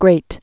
(grāt)